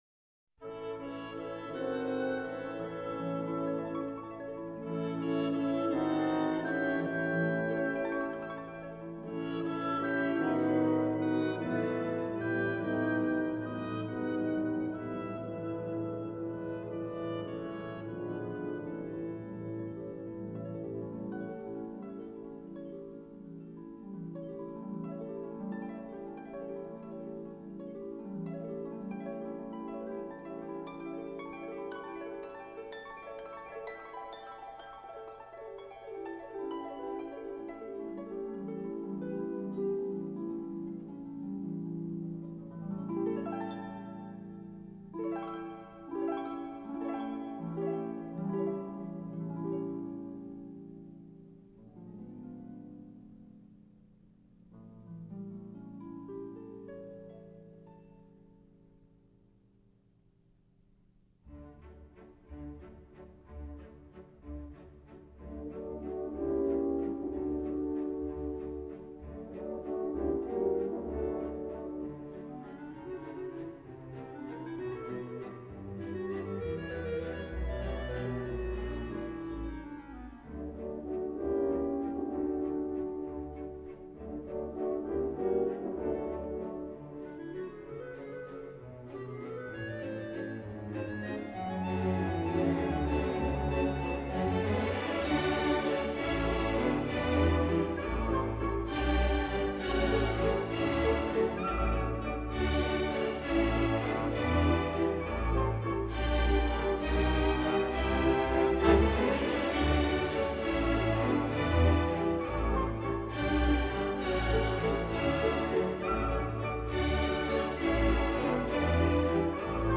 Relaxing-with-the-Classics-Disc-2-11-Nutcracker-ballet-Op.71-Waltz-of-the-Flowers-Classical.mp3